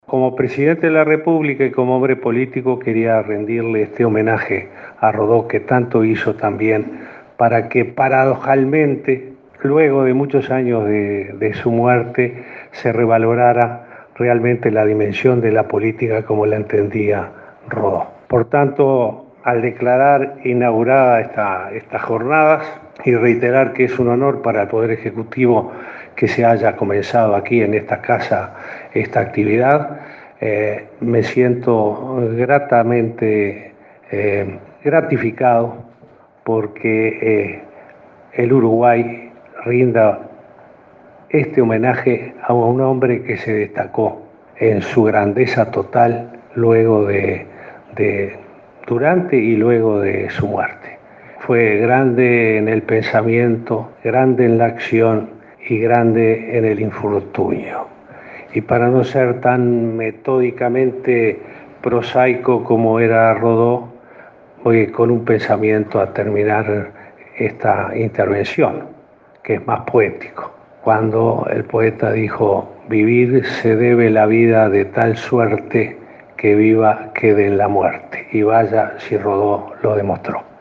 “Rindo homenaje a un hombre que se destacó en su grandeza total en vida y luego con su legado”, dijo el presidente, Tabaré Vázquez, en la apertura del congreso que conmemora los 100 años del fallecimiento de José Enrique Rodó.